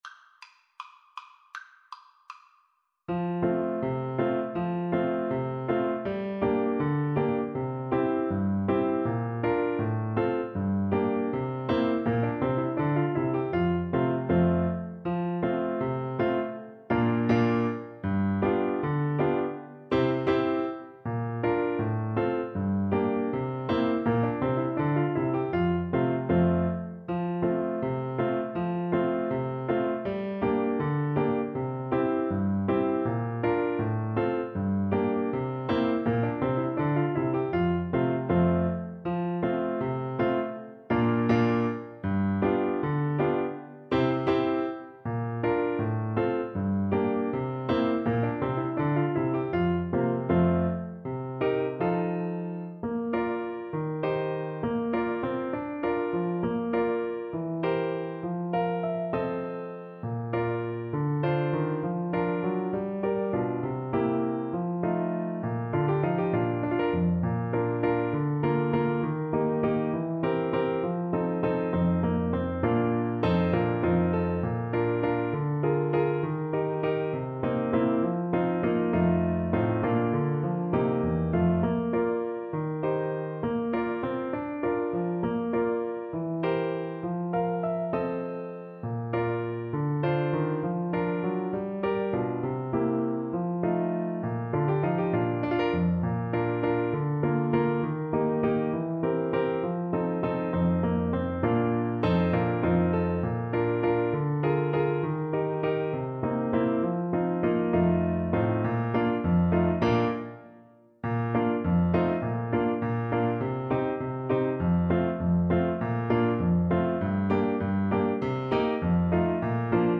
A medley of folk songs
4/4 (View more 4/4 Music)
F major (Sounding Pitch) (View more F major Music for Oboe )
Oboe  (View more Intermediate Oboe Music)